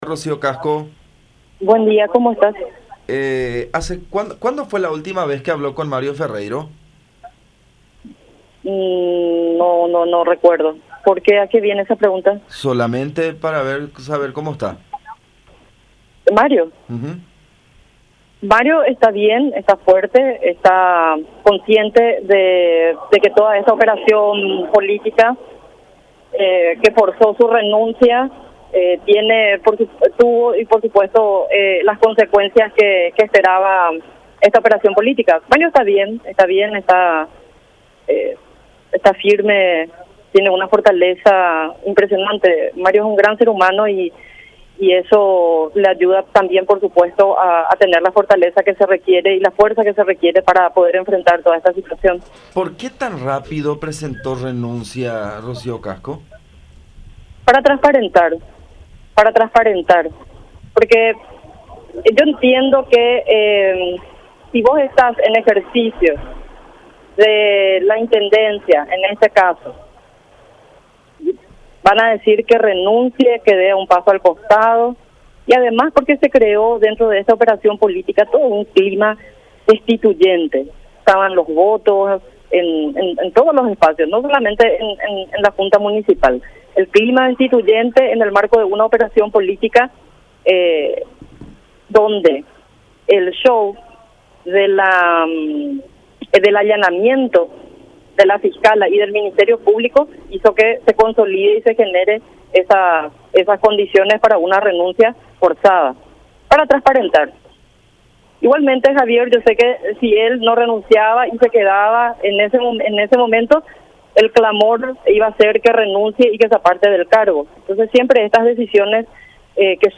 La exdiputada Rocío Casco habló sobre el estado de Mario Ferreiro, quien hasta el momento no ha hablado ni se ha pronunciado al respecto de la investigación de la "caja paralela" en la municipalidad de Asunción, la cual provocó su renuncia hace ya casi un mes.